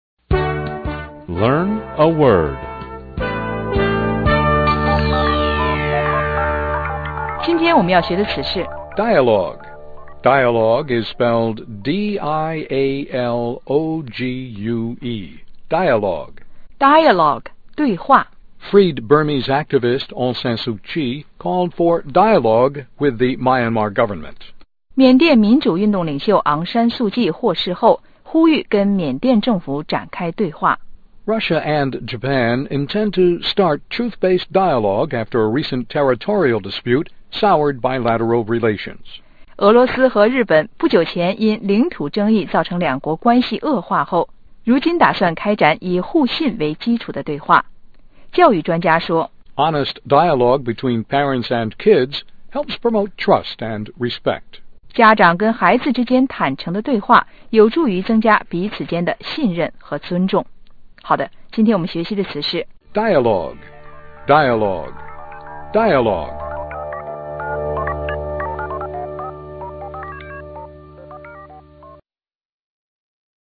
VOA英语教学, 1120 dialogue